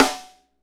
Index of /90_sSampleCDs/Northstar - Drumscapes Roland/DRM_Funk/SNR_Funk Snaresx
SNR FNK S0DR.wav